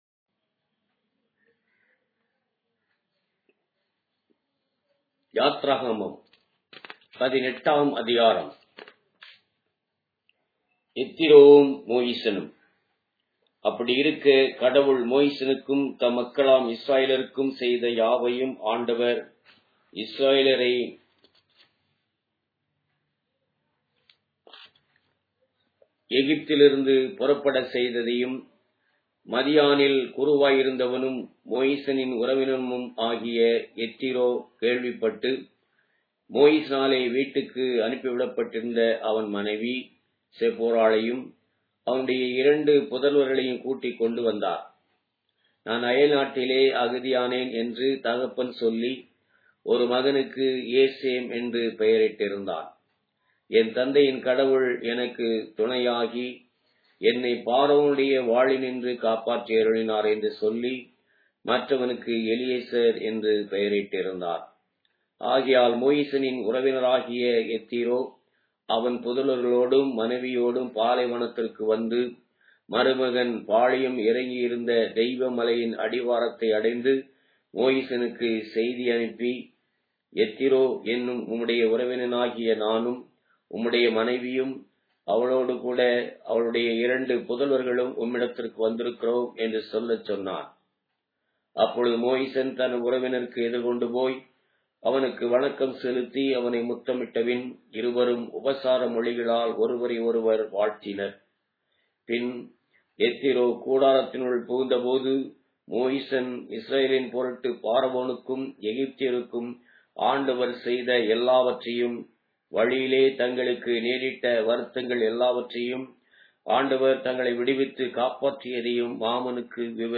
Tamil Audio Bible - Exodus 13 in Rcta bible version